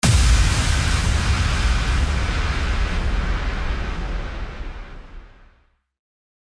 FX-1871-IMPACT
FX-1871-IMPACT.mp3